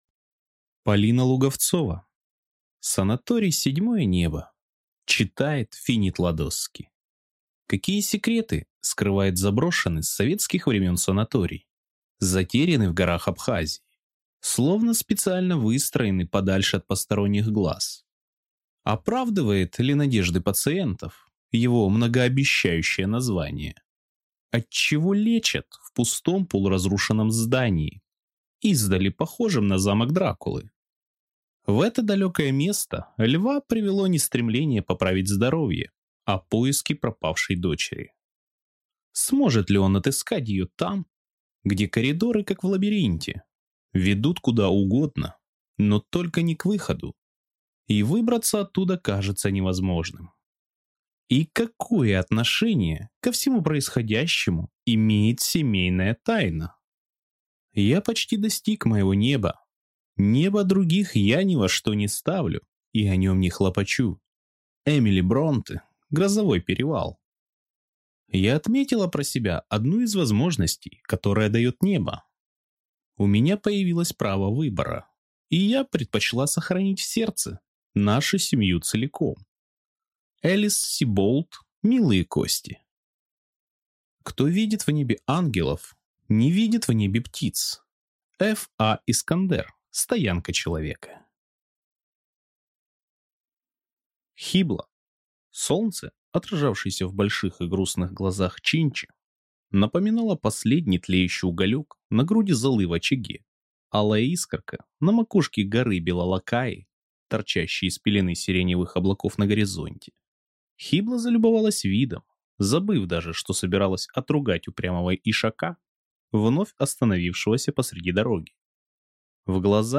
Аудиокнига Санаторий «Седьмое небо» | Библиотека аудиокниг